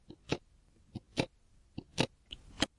电动计数器滴答声
描述：电动计数器滴答作响。
Tag: 放射性的 噪声 数字 音效 计数器 滴答 盖格 声音 辐射